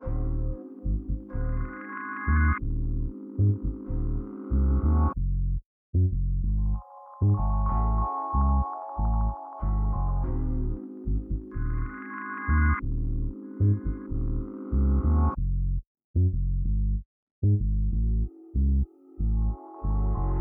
AV_Grind_Bass_94bpm_C#min
AV_Grind_Bass_94bpm_C-min.wav